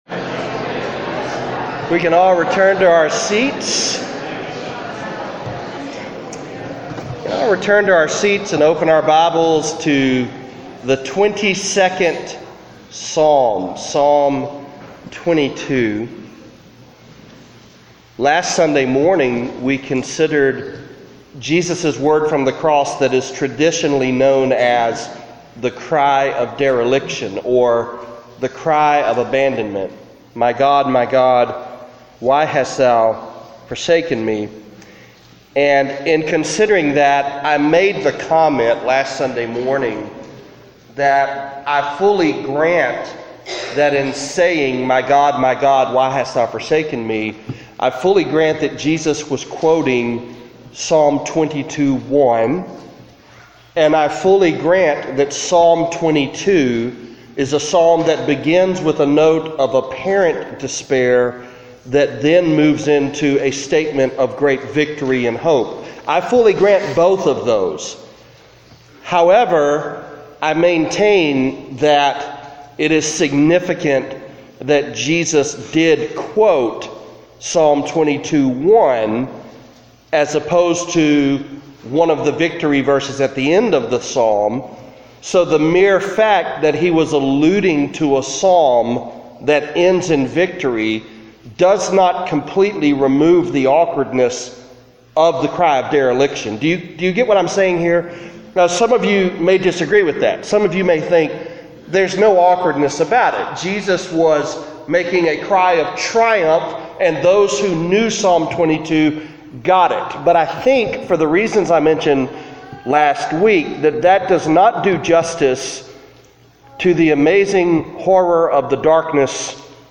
Psalm 22 (Preached on March 18, 2018, at Central Baptist Church, North Little Rock, AR)